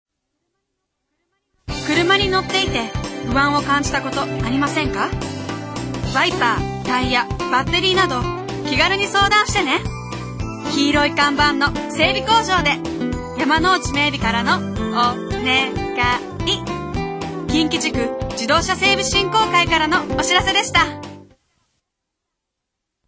ラジオＣＭは、ＭＢＳ（毎日放送ラジオ）にて９月から11月の３ヶ月間２つの番組で20秒ＣＭを番組提供で放送します。